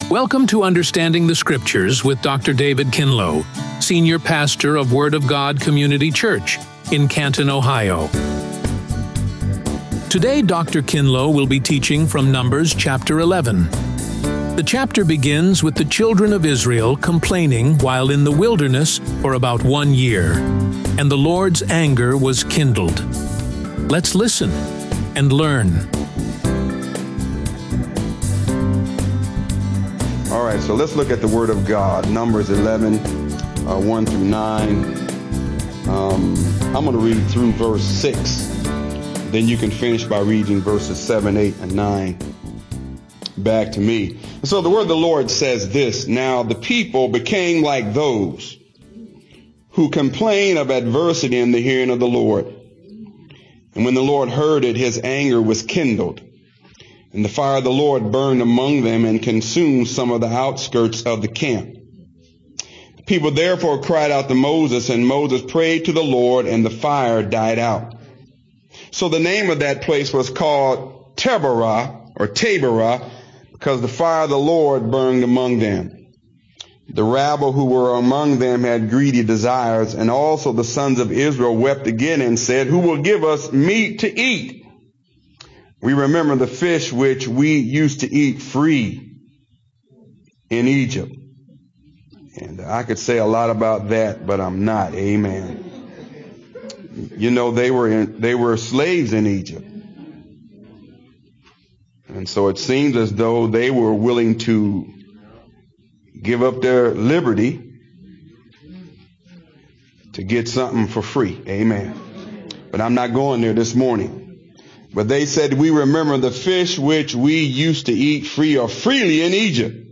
Radio Sermons | The Word of God Community Church
RADIO SERMON